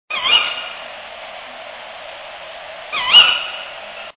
Richiami della femmina (f>)
Strix-aluco-2.mp3